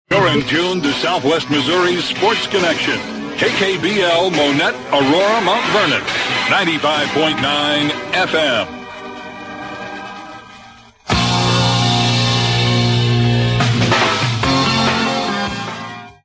KKBL Top of the Hour Audio: